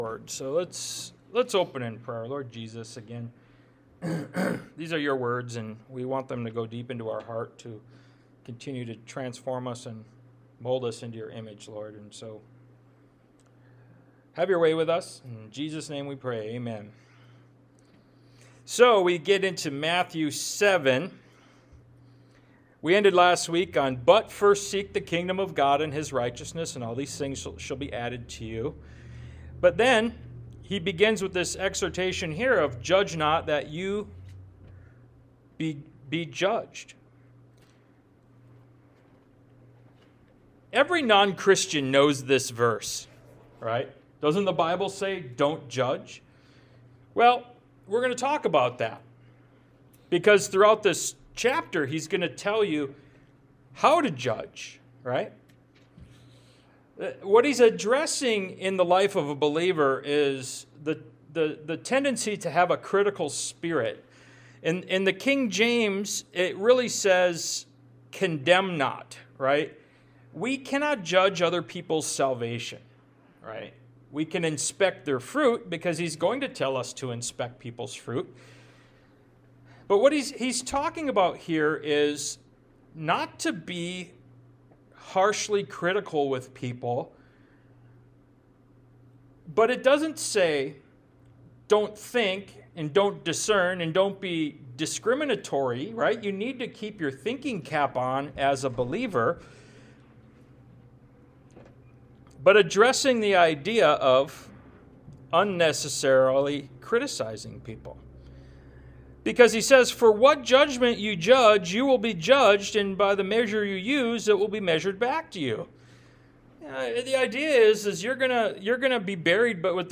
Ministry of Jesus Service Type: Sunday Morning « “Perspective” Ministry of Jesus Part 21 “Jesus is Life” Ministry of Jesus Part 23 »